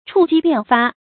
触机便发 chù jī biàn fā 成语解释 机：弓弩上的发箭器。原指弓弩上的发箭器，一经触发，箭便射出。